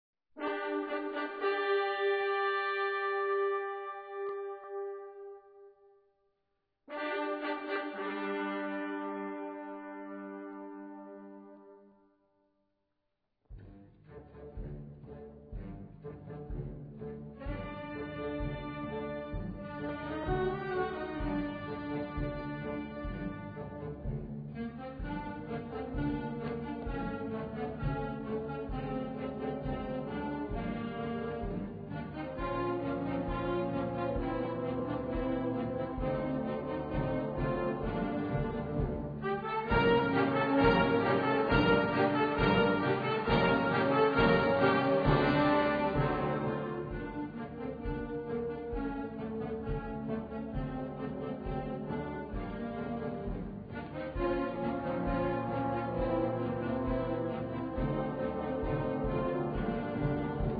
Gattung: Selection
Besetzung: Blasorchester